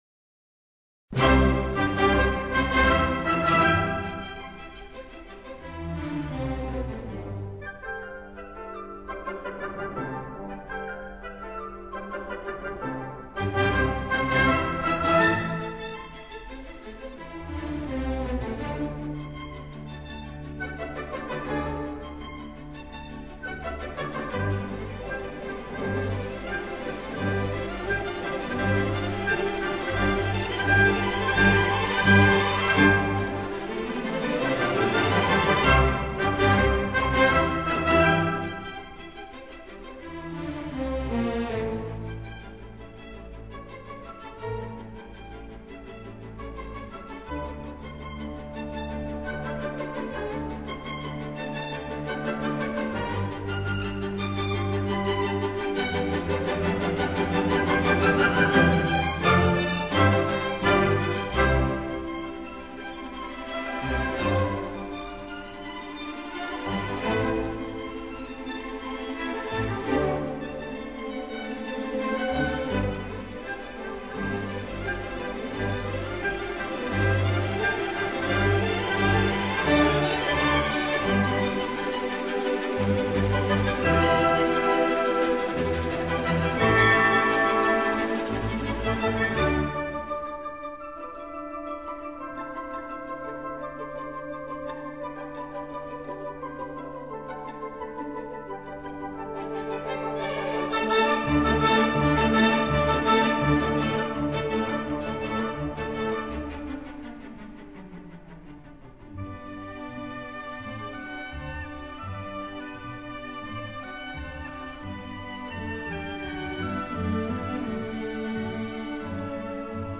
【膠復刻CD】
第一號交響曲充滿著純真的青春氣息，旋律優美、色彩鮮明，配器華麗而充滿巧思，是一首不可多得的傑作。